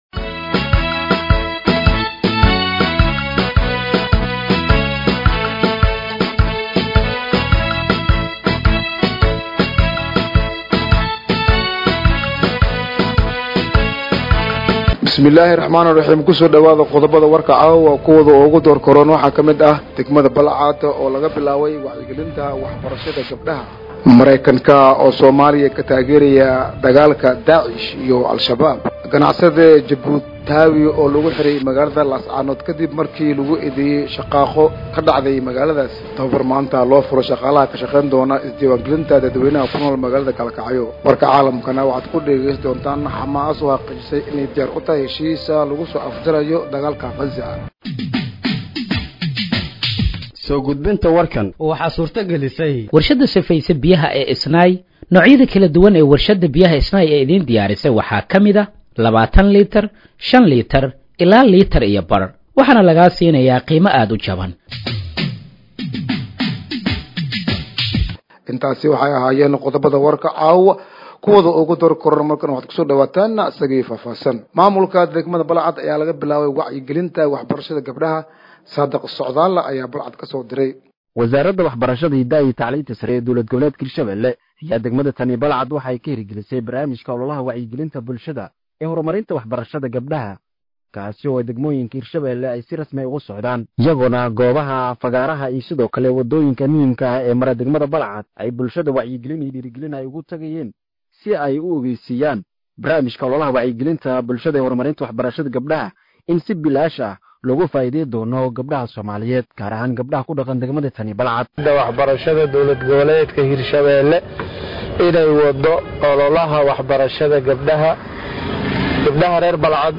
Dhageeyso Warka Habeenimo ee Radiojowhar 04/09/2025